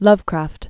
(lŭvkrăft), H(oward) P(hillips) 1890-1937.